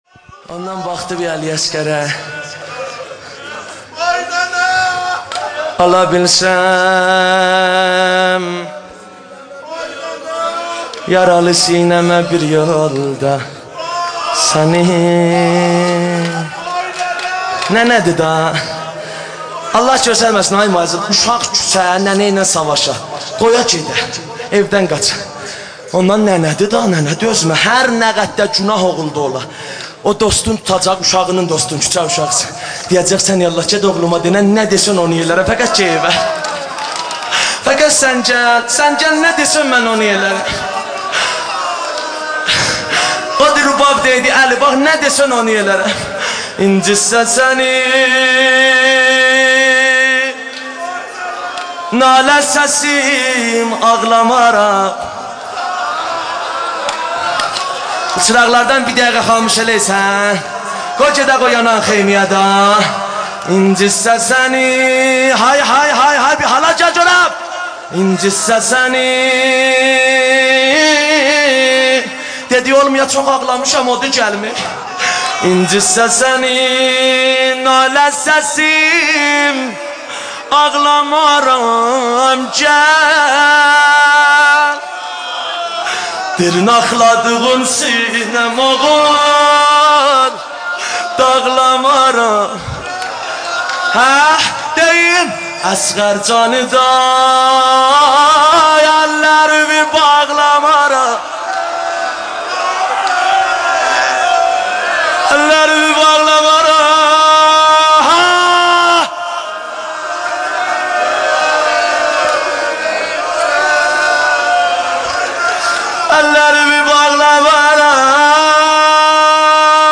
مداحی
دو نوحه زیبا و سوزناک
نوحه دوم